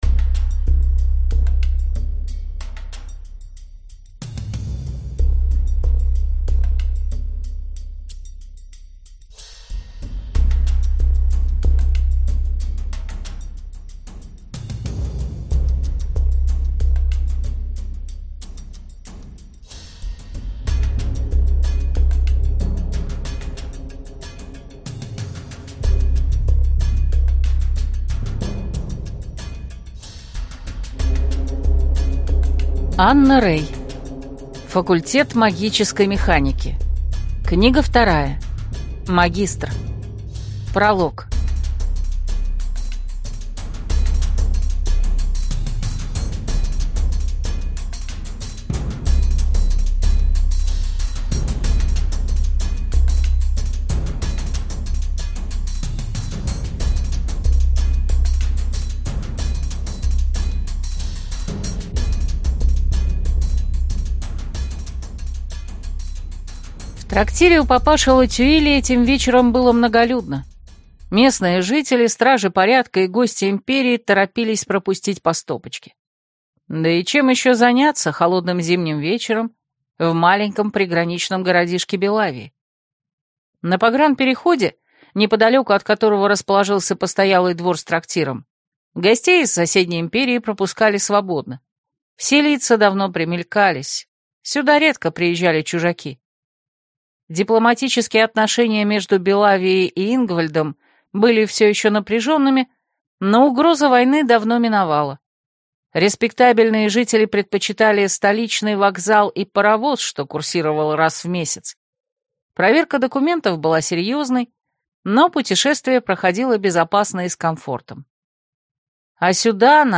Аудиокнига Магистр по желанию | Библиотека аудиокниг